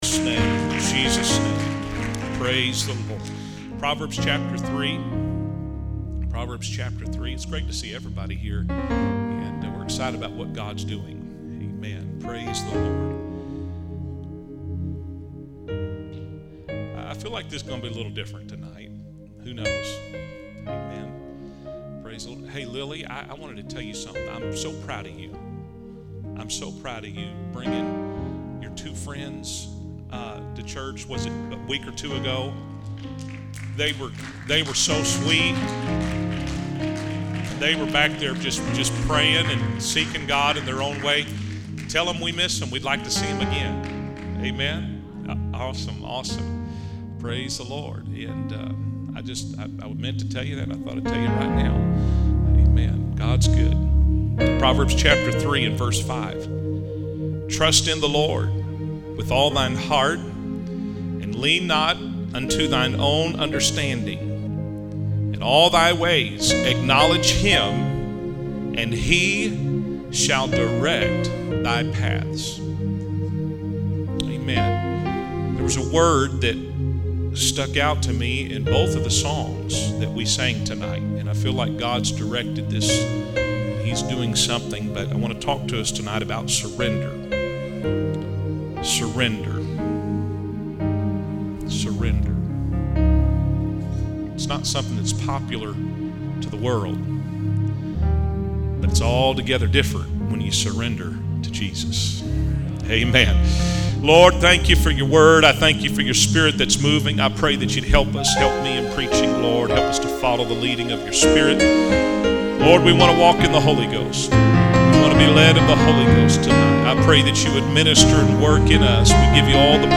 Through poignant anecdotes and profound insights, this sermon challenges believers to release their grip on fear, doubt, and uncertainty, and to surrender fully to the unfailing guidance of God.